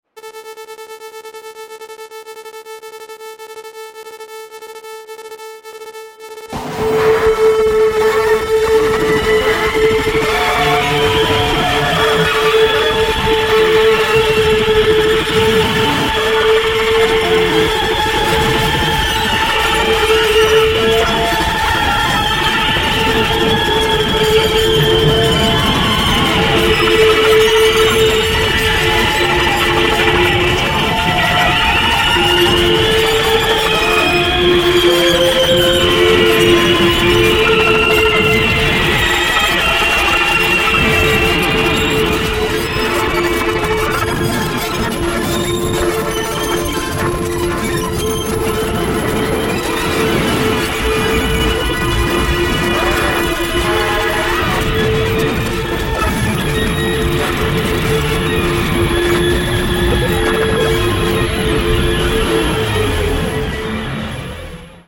electric guitar/electronics